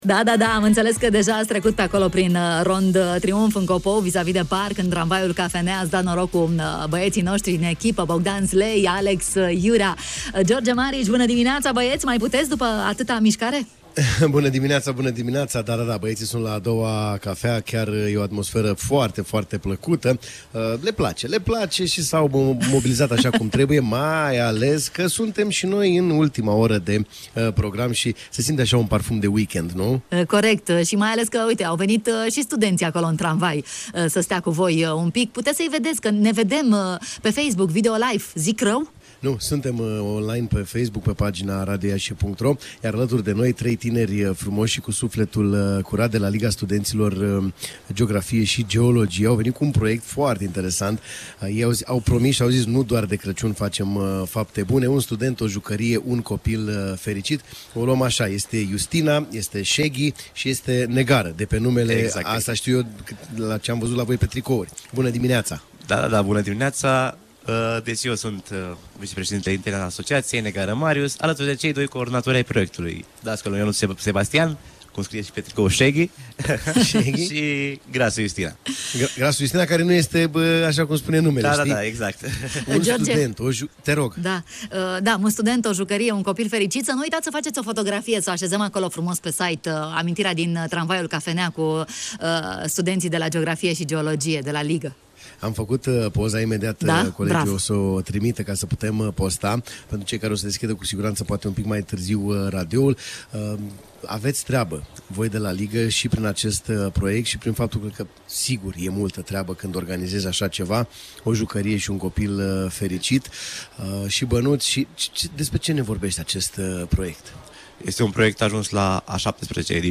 au fost în direct la matinalul de la Radio Iași: